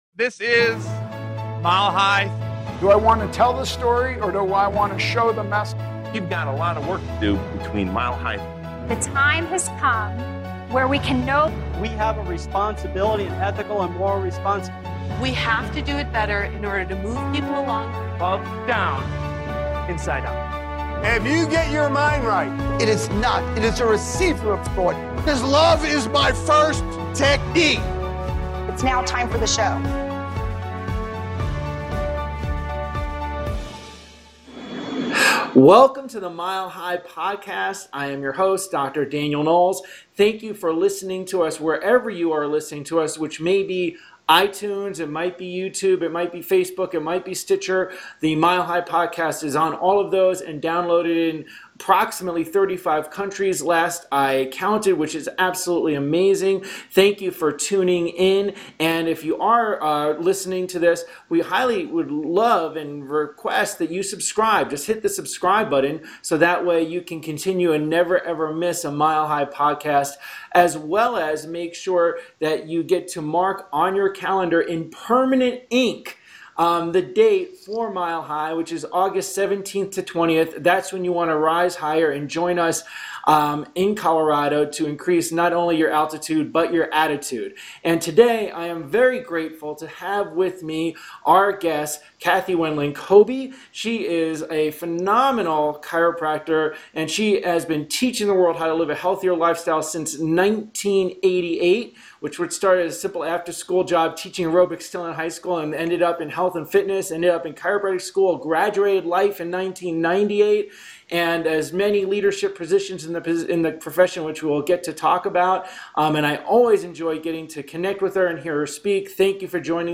[/bullet_block] [vertical_spacing height=”5″] Enjoy this high energy episode!